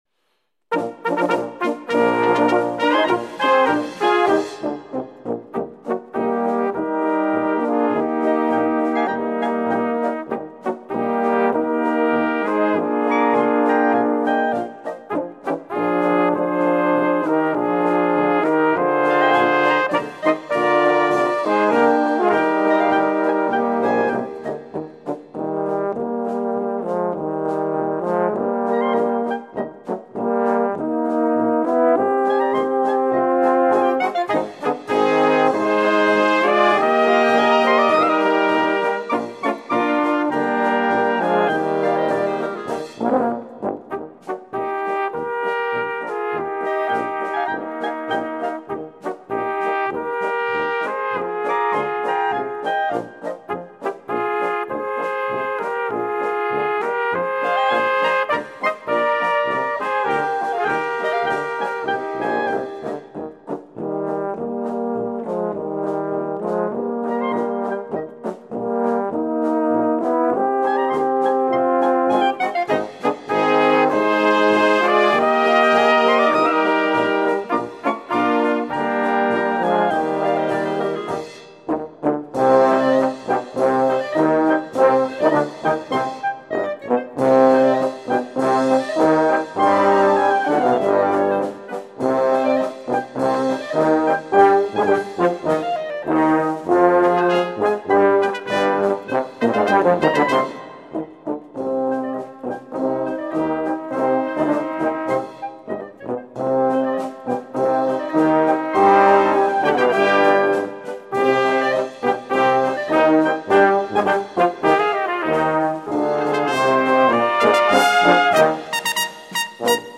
Gattung: Konzertpolka
Besetzung: Blasorchester